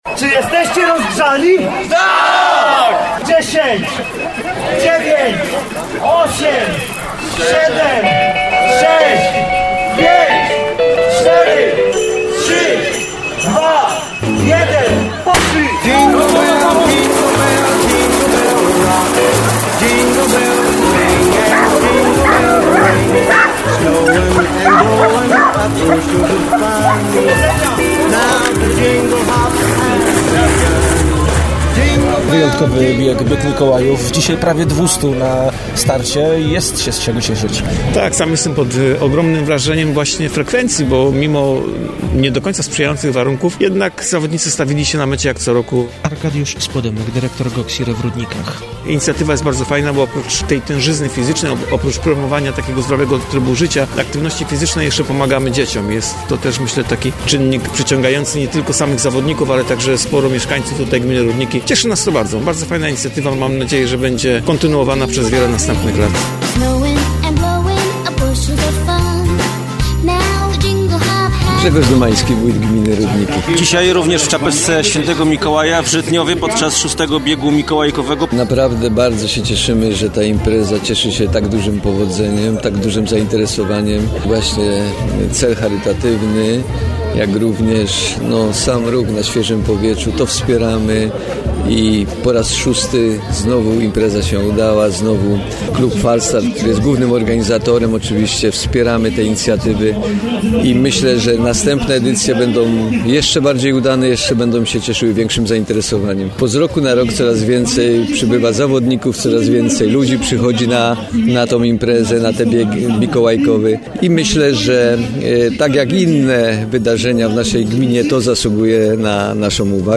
Posłuchaj relacji z wydarzenia: